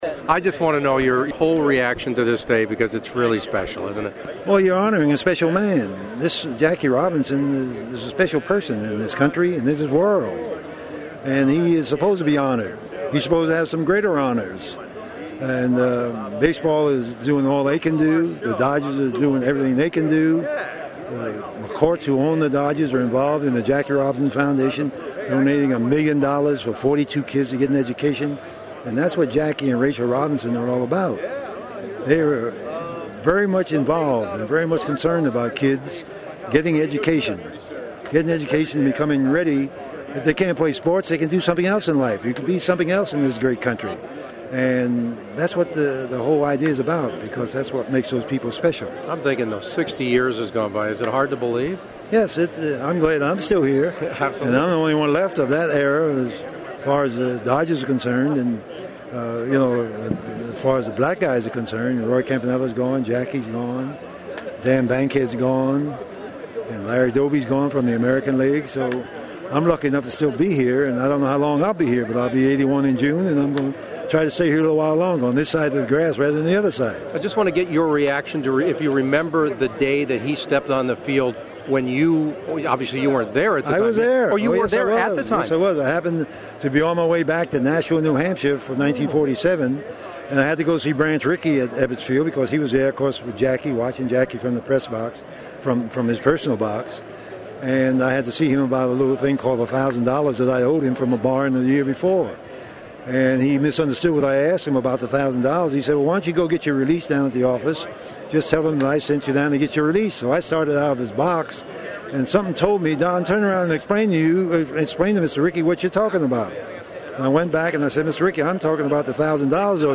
on Jackie Robinson Day 2007